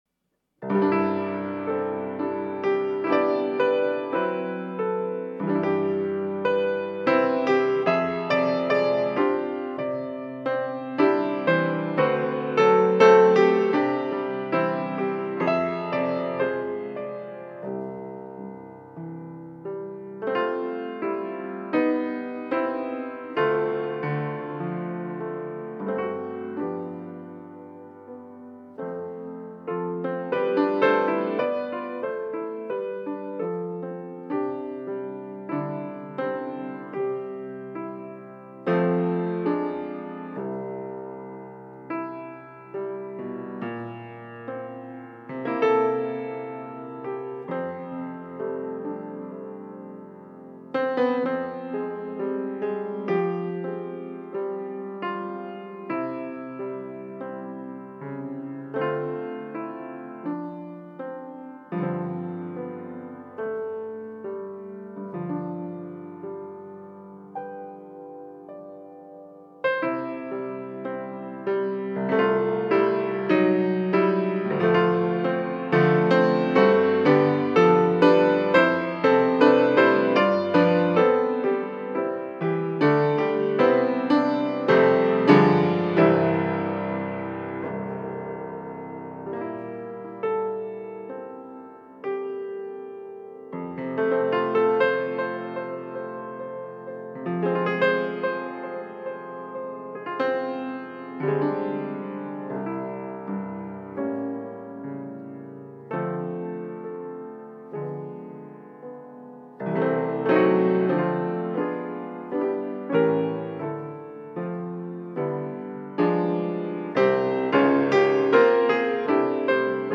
13首JAZZ PIANO
簡潔純粹的鋼琴音符，時而沉靜溫暖，時而俏皮活潑，整張專輯以jazz手法呈現濃厚的現代都會風格。